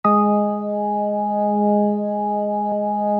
B3LESLIE G#4.wav